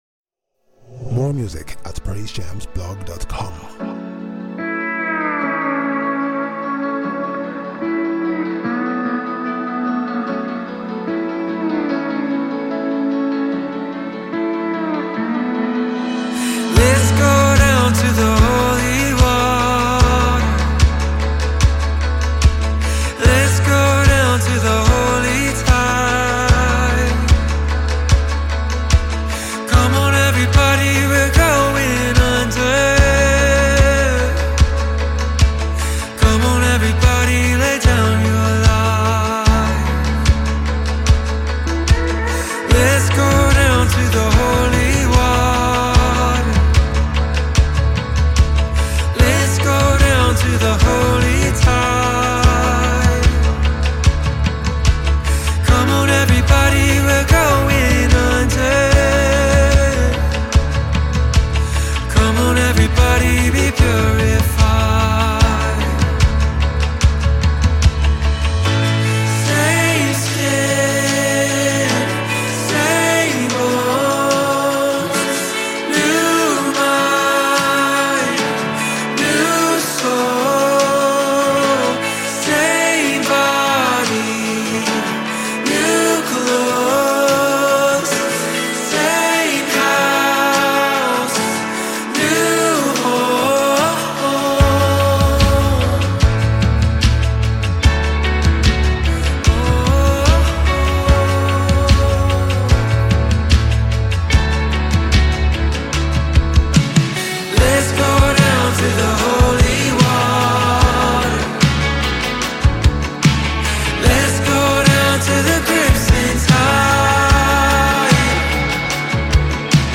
Dynamic gospel recording artist
Throughout this inspirational song